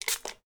SPRAY_Manual_RR1_mono.wav